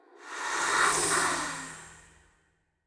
Xerah-Vox_Whisper_jp.wav